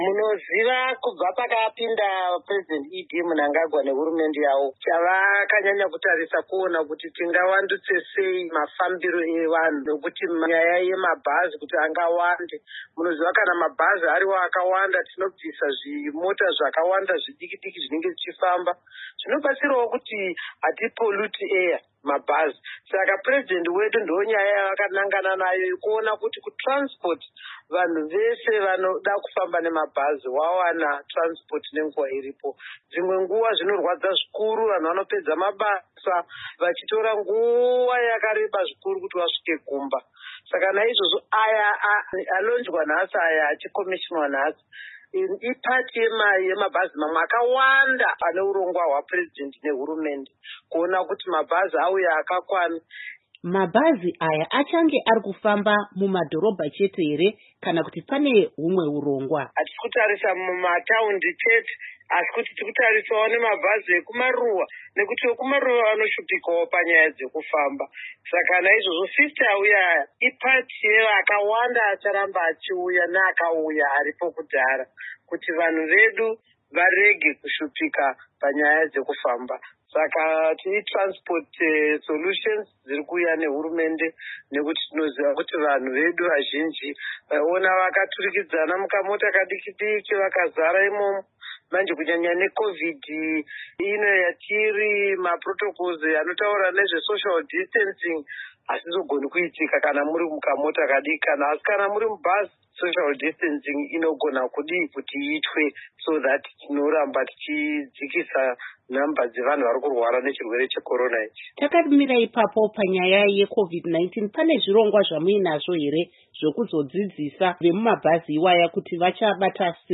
Hurukuro naAmai Monica Mutsvangwa